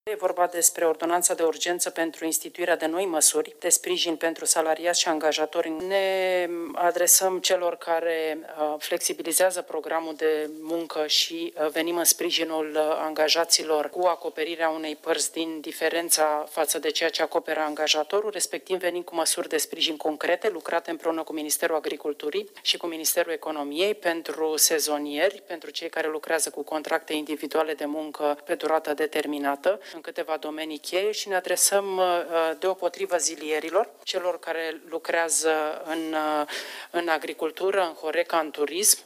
Măsuri de sprijin sunt aprobate azi la Guvern pentru angajatorii și angajații afectați de criză, cum a explicat ministrul Muncii, Violeta Alexandru:
16iul-20-Violeta-despre-sprijin-angajati.mp3